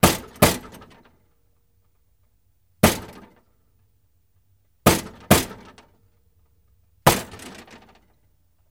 Coke Machine Hits